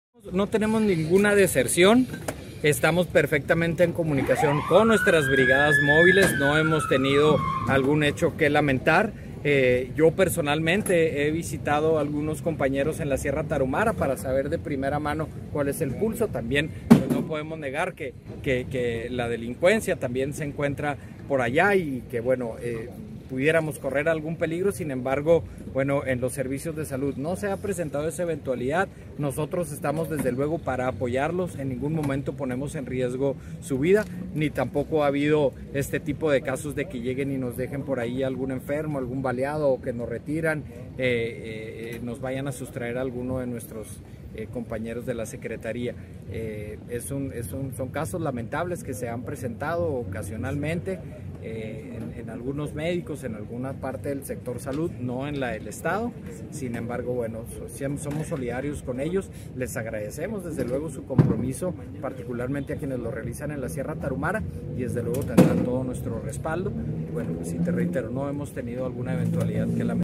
AUDIO: GILBERTO LOYA CHÁVEZ, TITULAR DE LA SECRETARÍA DE SALUD ESTATAL